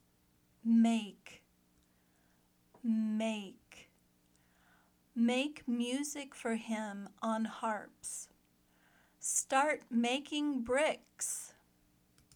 /meɪk/ (verb)